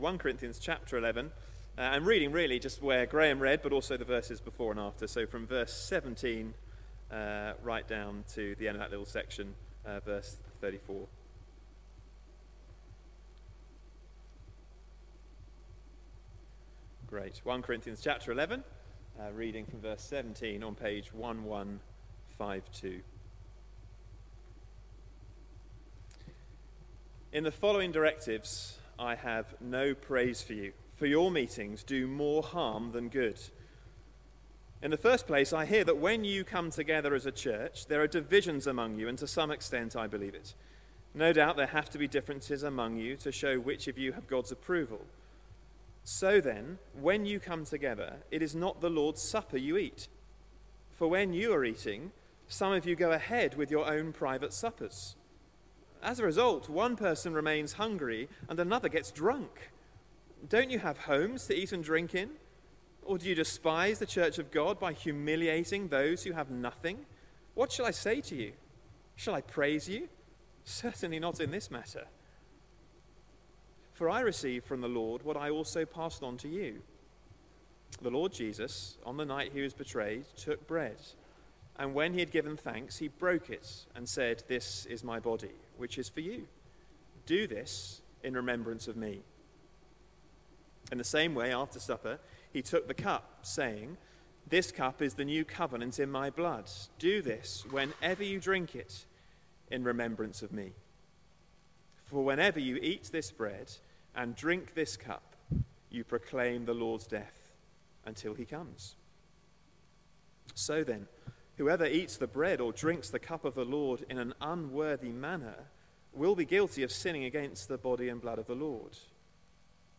Sunday Service | Christ Church Crouch End